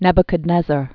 (nĕbə-kəd-nĕzər, nĕbyə-) 630?-562 BC.